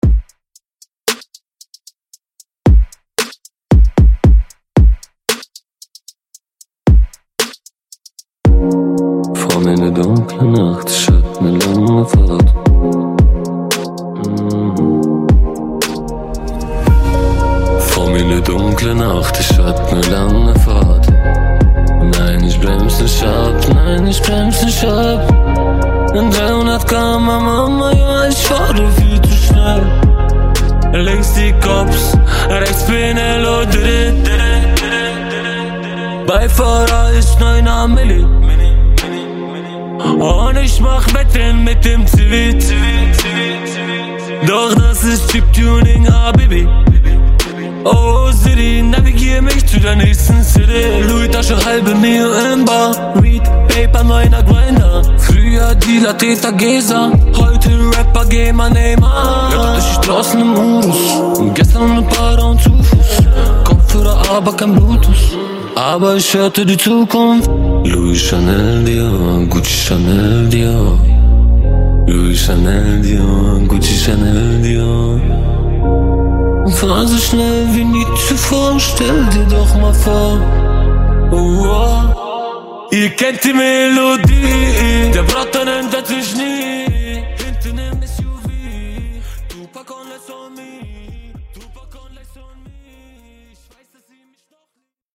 Genre: GERMAN MUSIC
Clean BPM: 80 Time